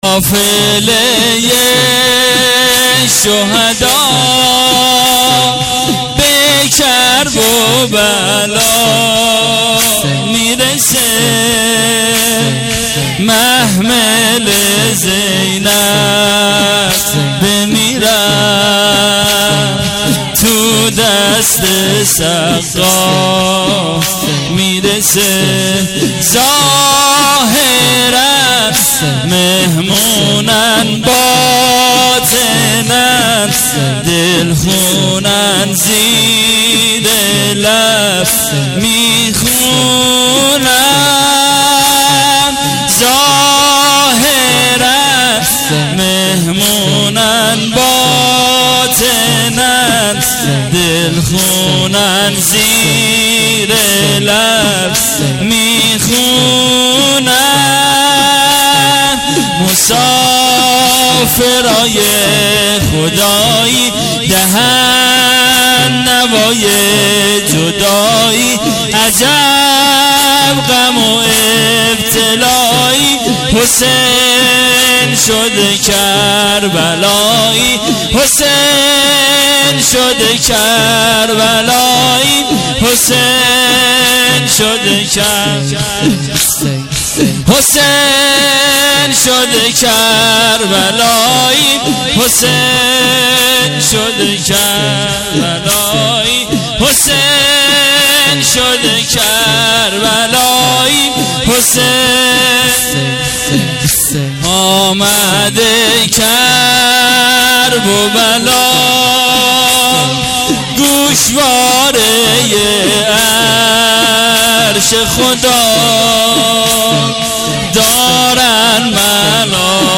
شب دوم محرم الحرام ۱۳۹۸